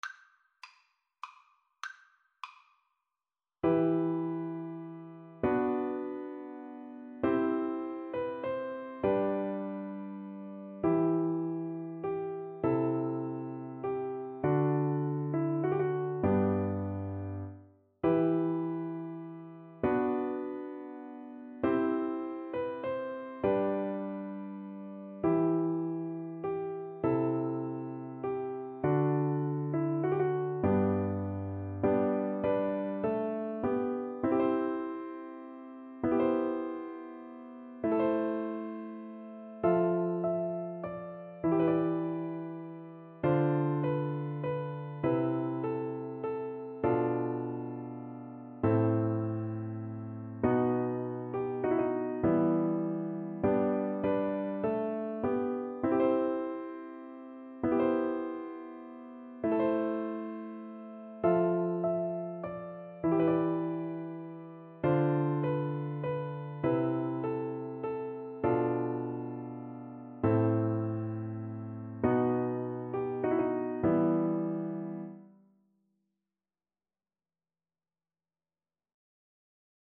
Play (or use space bar on your keyboard) Pause Music Playalong - Piano Accompaniment Playalong Band Accompaniment not yet available reset tempo print settings full screen
E minor (Sounding Pitch) (View more E minor Music for Viola )
Classical (View more Classical Viola Music)